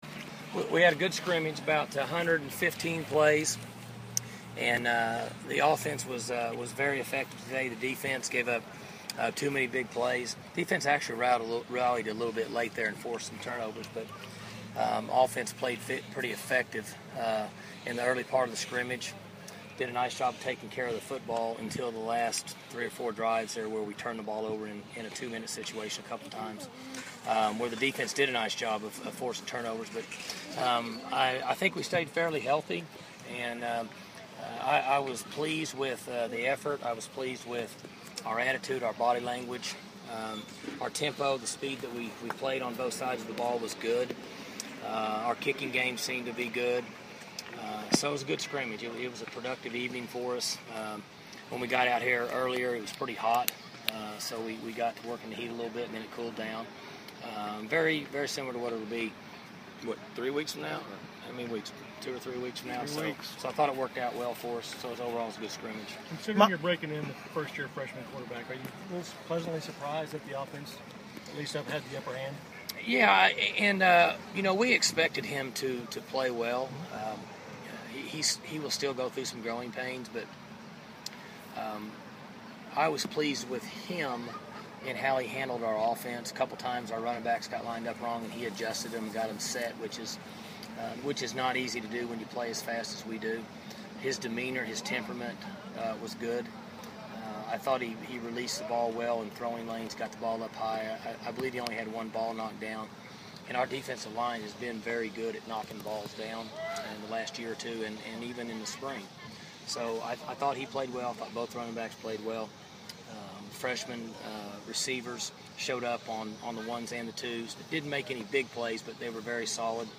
Coach Gundy shares his thoughts on how the Cowboy Football team performed in their first scrimmage of Fall Camp 2012.